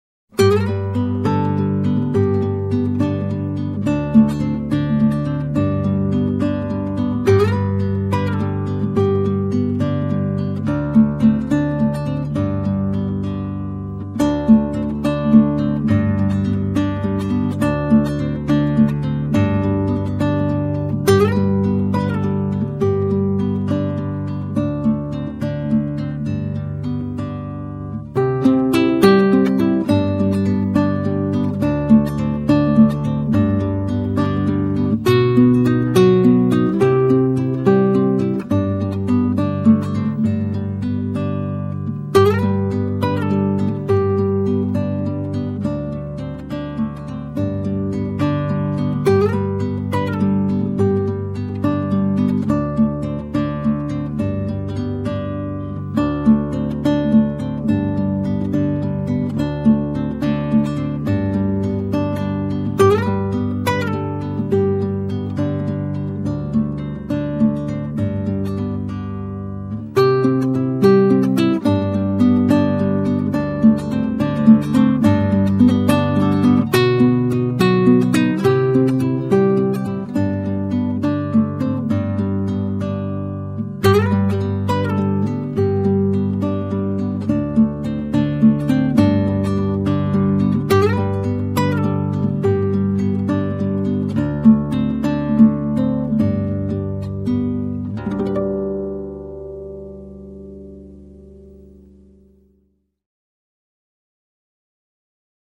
spanishromance.mp3